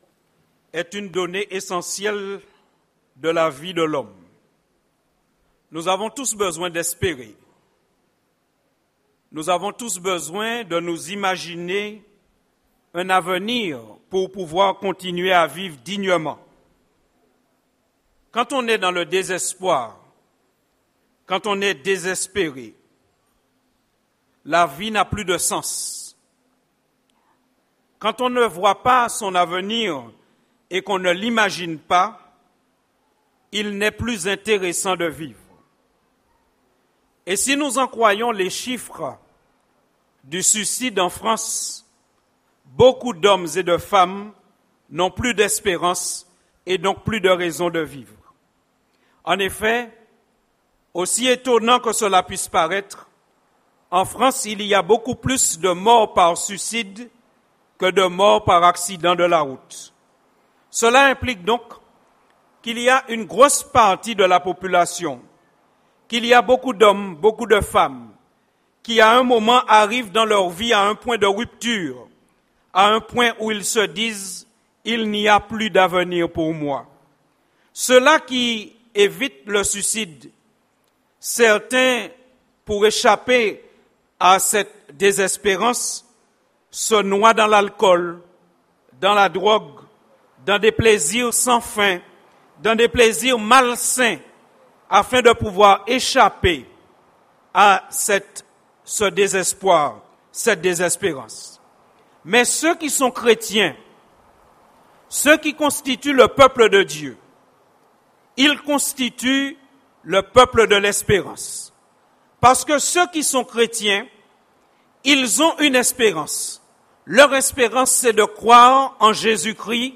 Sermons Semaine de prière